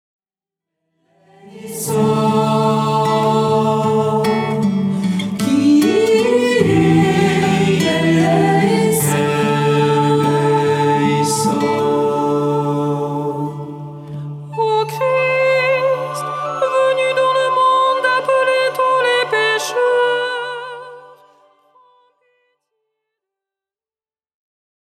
Thème : Messe
Usage : Liturgie